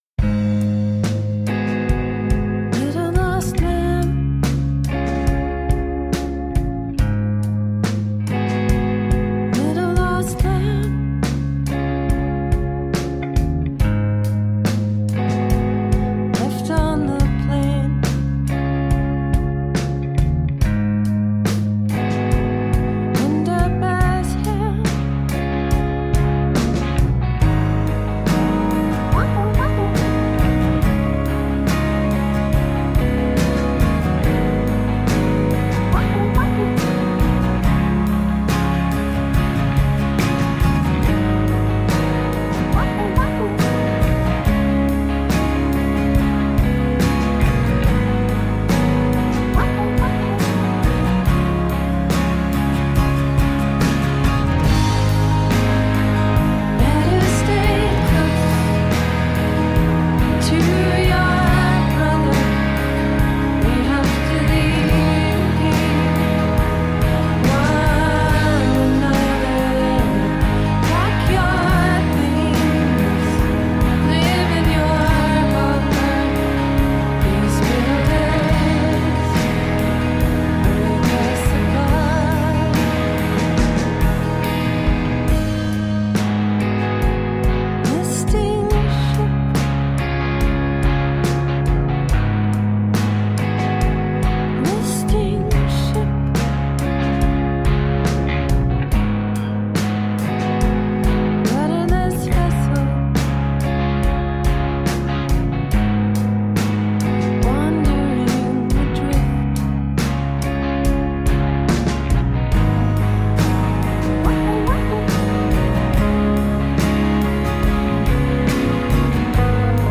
Dreamy, slightly mournful indie pop.
Tagged as: Electro Rock, Pop